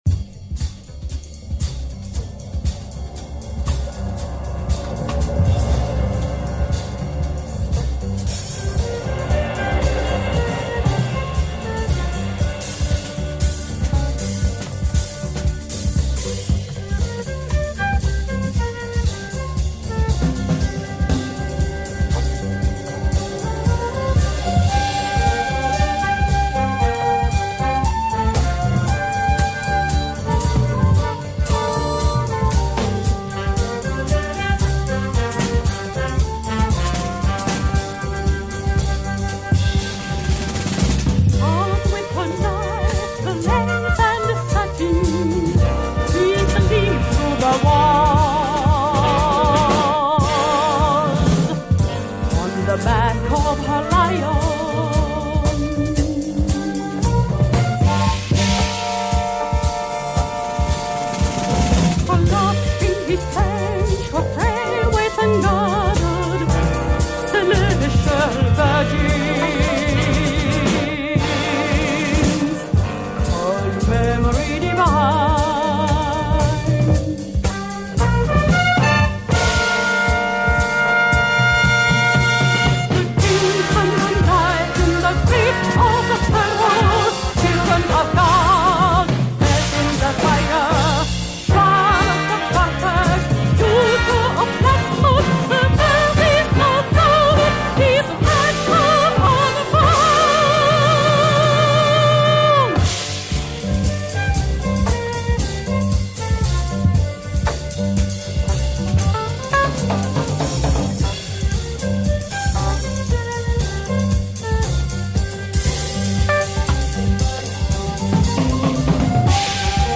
Superb big band jazz / fusion album from Finland
female vocals